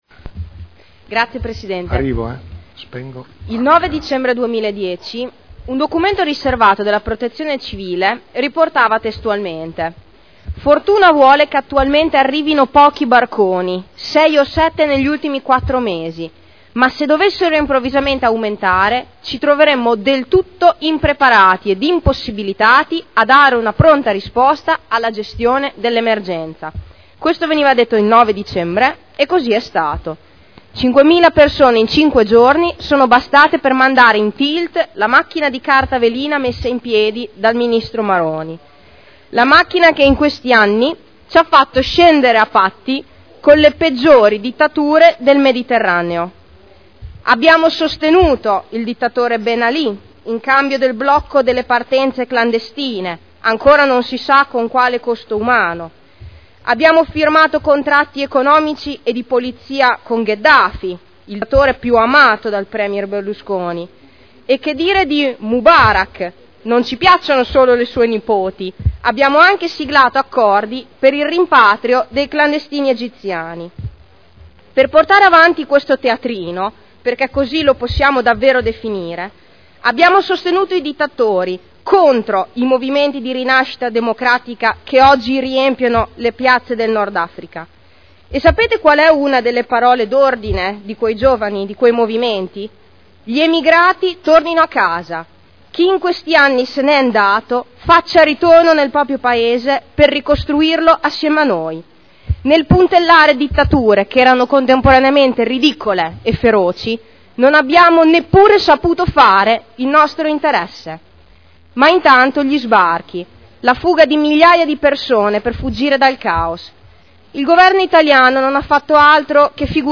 Seduta del 24/02/2011. Interviene sugli Ordini del Giorno riguardanti gli avvenimenti in Libia.